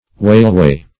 weyleway - definition of weyleway - synonyms, pronunciation, spelling from Free Dictionary Search Result for " weyleway" : The Collaborative International Dictionary of English v.0.48: Weyleway \Wey"le*way\, interj.